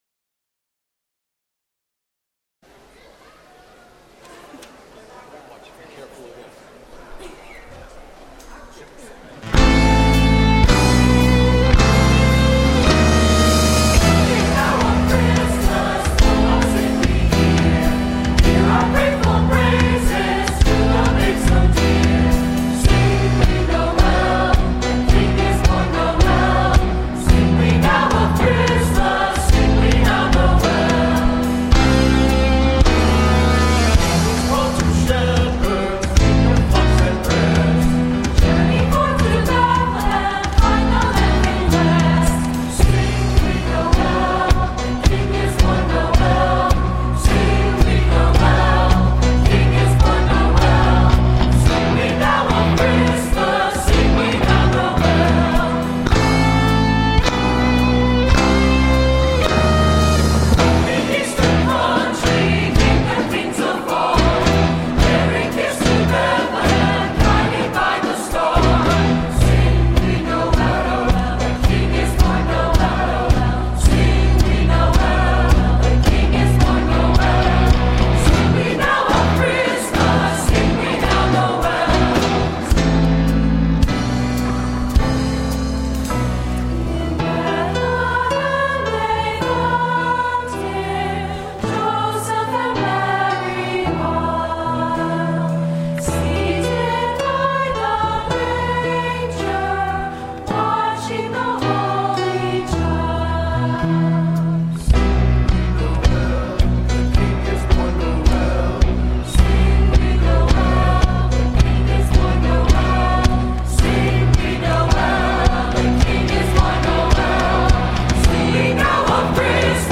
Our Christmas message series will begin this Sunday titled, “Heaven and Nature Sing”. Some of our favorite Christmas hymns are steeped in doctrine and bring forth in worship what the Word has proclaimed.